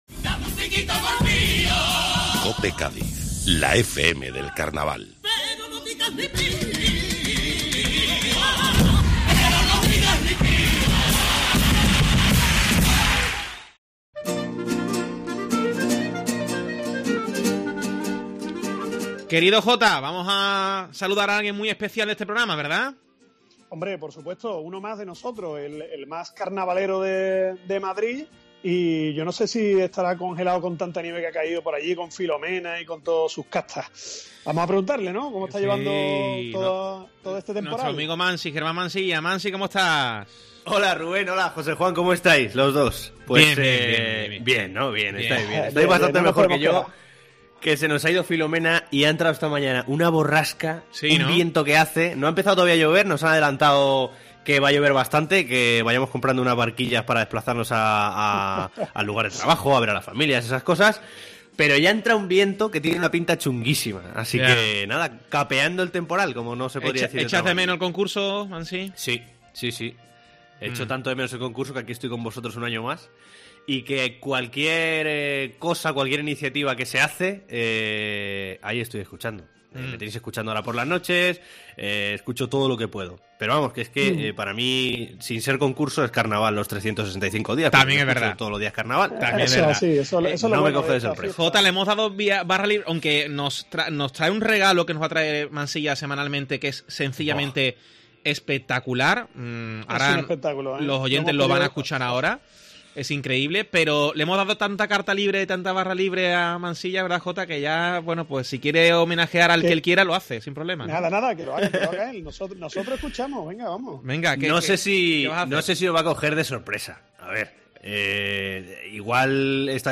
Escucha lo mejor de los pasodobles del autor gaditano dedicados a uno de los grandes temas del Carnaval de Cádiz